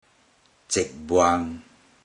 Click each Romanised Teochew word to listen to how the Teochew word is pronounced.
zeig10buan0